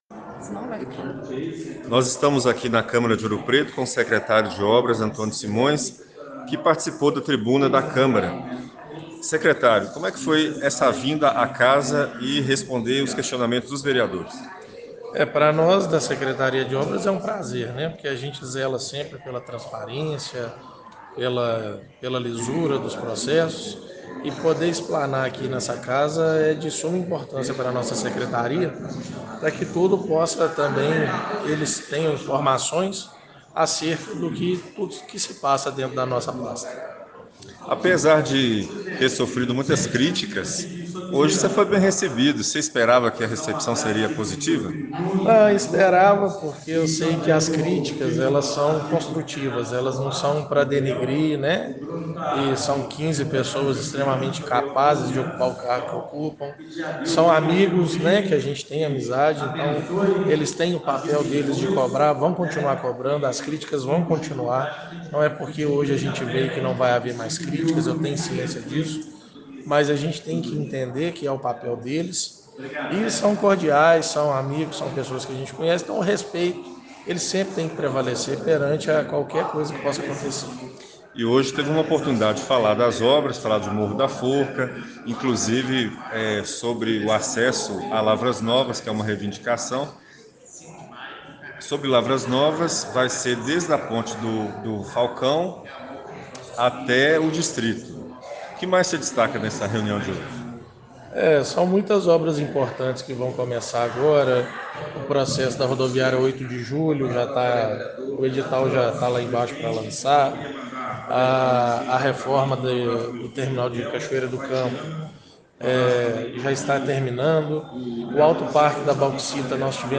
Entrevista com o Secretário de Obras, Antônio Simões. Audio gerado para a rádio Provincia FM.